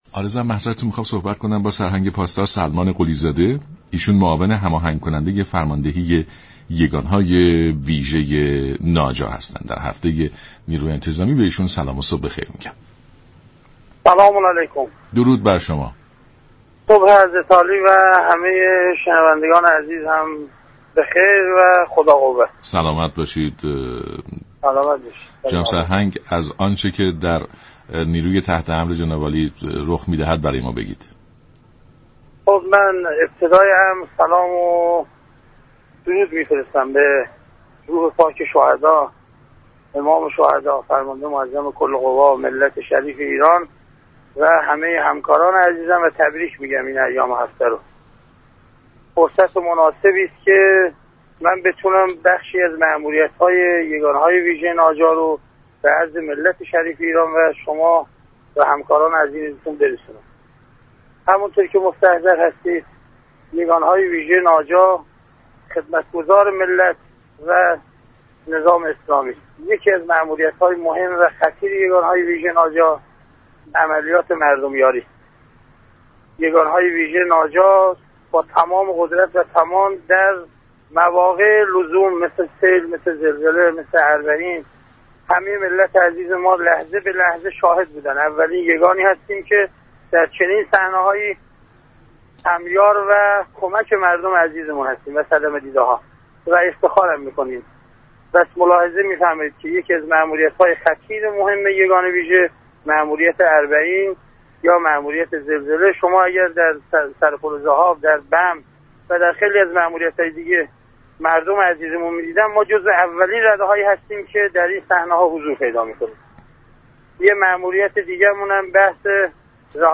معاون هماهنگ كننده فرماندهی یگان های ویژه ناجا در برنامه سلام صبح بخیر رادیو ایران گفت: فعالیت های ناجا در راستای ارتقای امنیت،كمك به مردم و تامین نظم و برقراری بهتر امنیت در كشور انجام می شود.